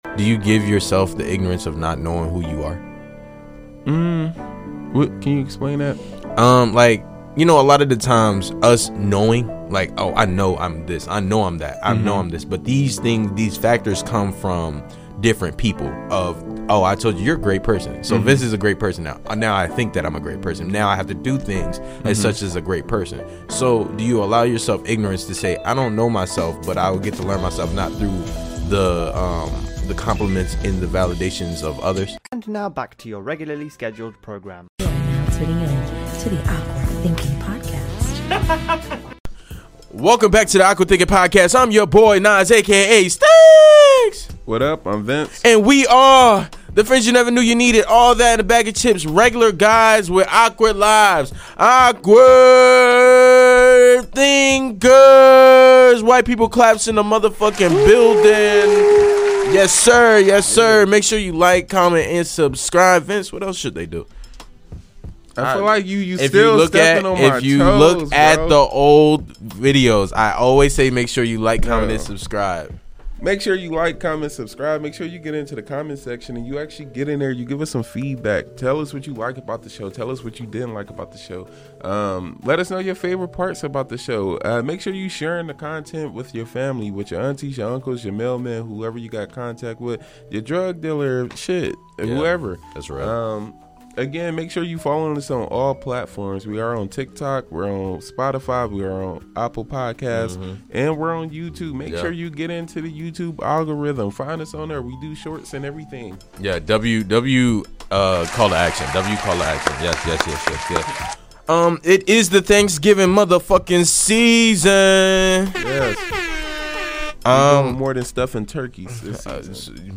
two long-time friends with a very peculiar outlook on life.